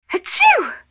sneeze.wav